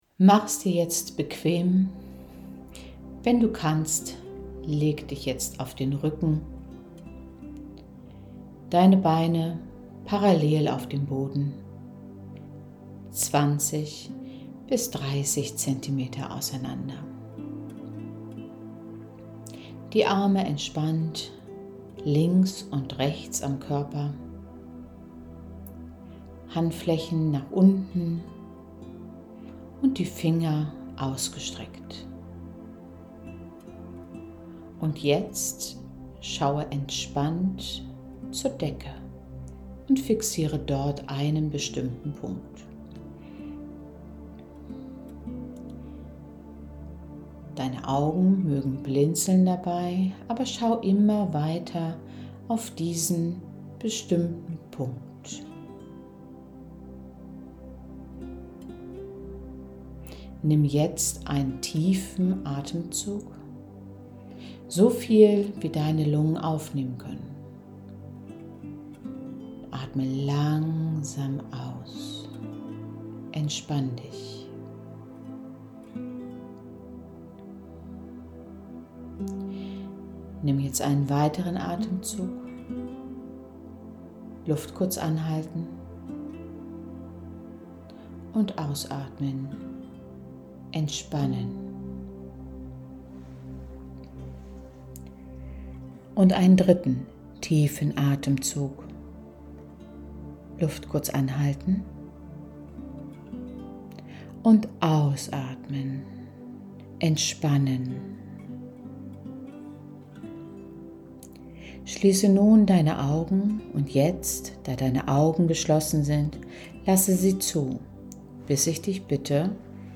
Selbsthypnose - Audiodatei:
Audio_Abschalten-Energie-und-Selbstvertrauen_mit-Musik.mp3